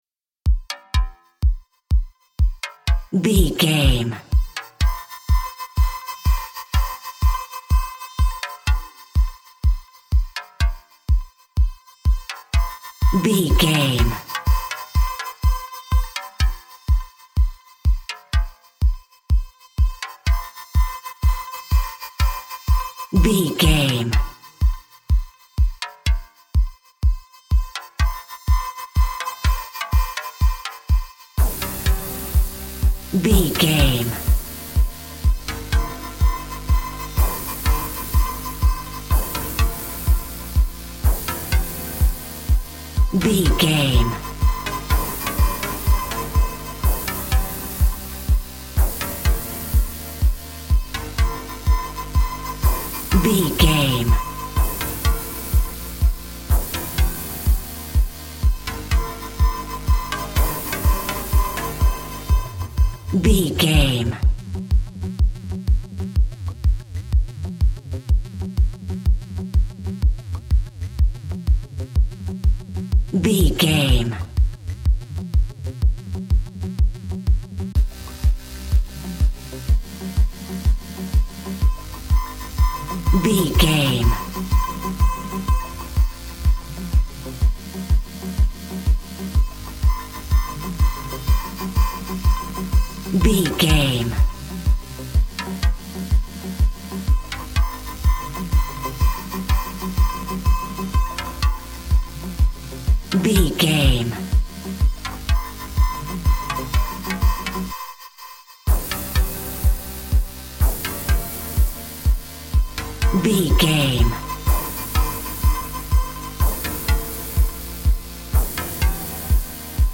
Groovy Modern Electronic Alt.
Ionian/Major
E♭
dreamy
ethereal
calm
happy
hopeful
synthesiser
drums
drum machine
house
electro dance
synth leads
synth bass
upbeat